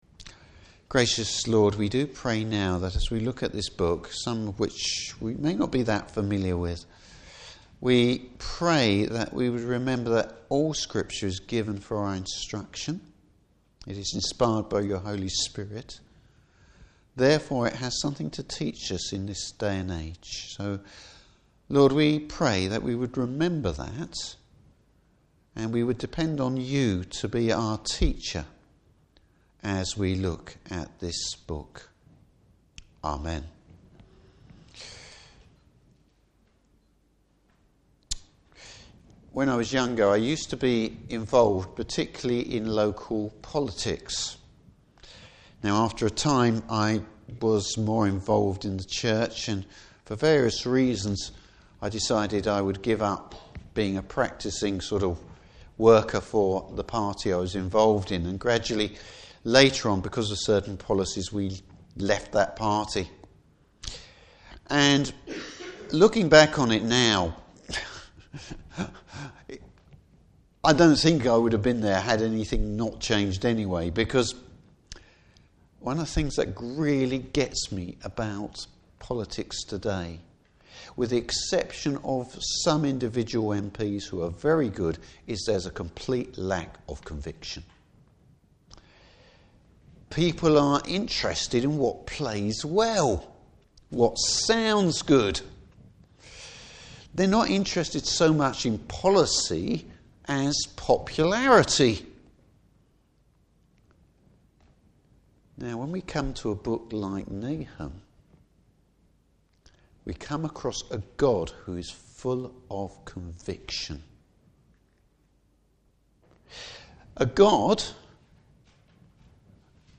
Service Type: Evening Service The character of God.